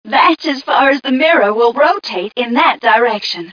mission_voice_m4ca022.mp3